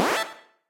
spawn_1.ogg